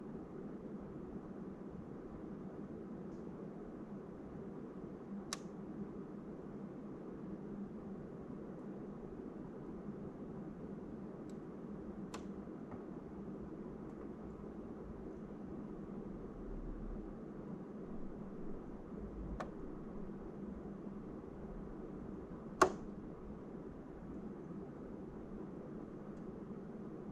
Bruits dilatation très fréquents installation récente PAC Mitsubishi - Question Forum Climatisation